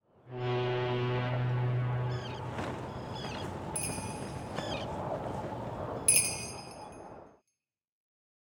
amb_第二章结尾.ogg